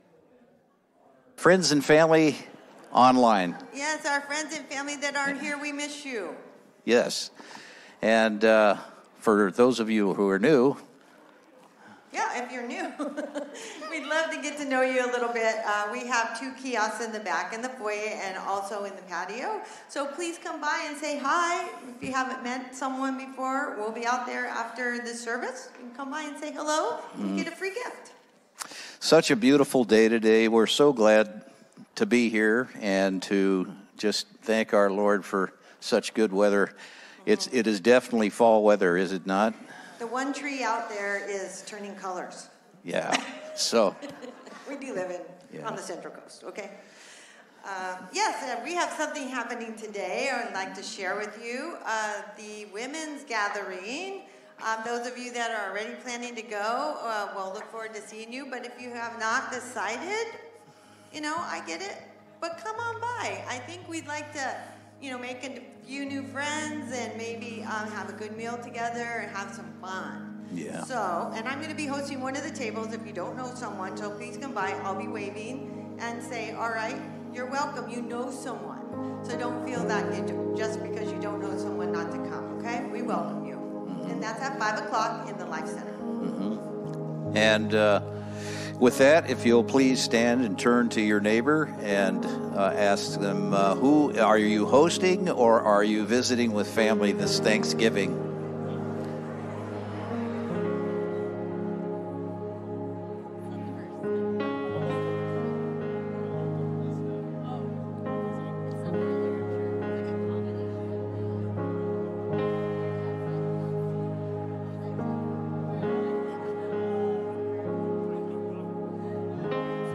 Sermons - N E W L I F E